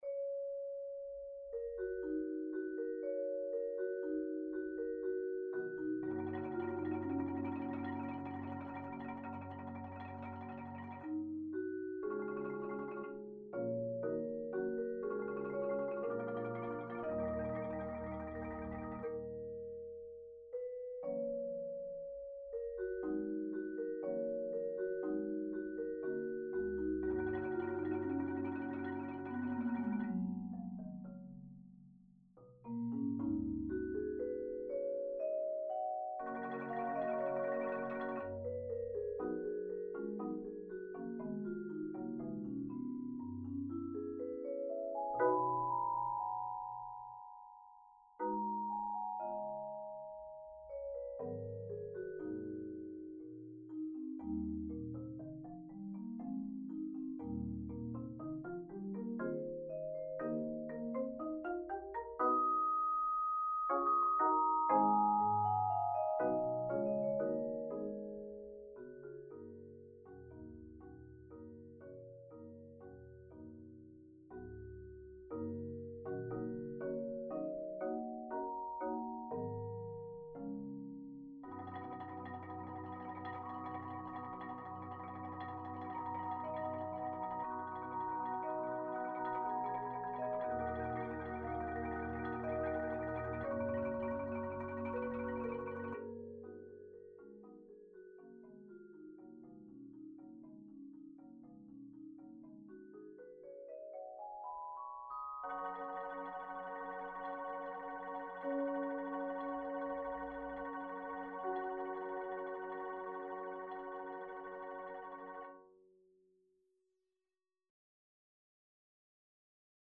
Genre: Percussion Ensemble
Vibraphone
Marimba 1 (4-octave)
Marimba 2 (5-octave)